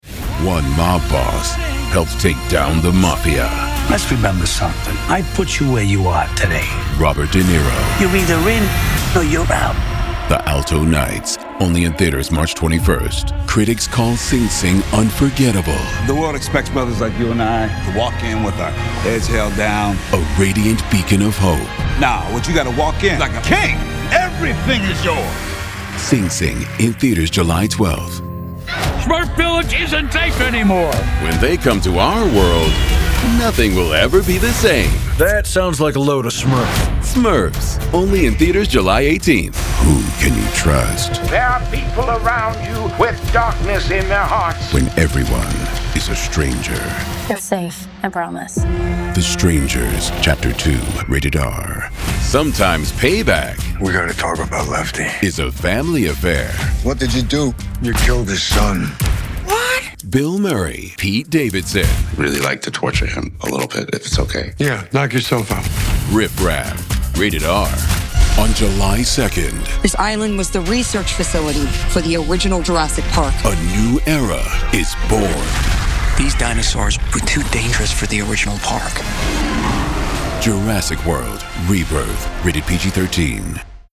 Male, Voice-Over for Commercials, Promos, Trailers, Narration and more.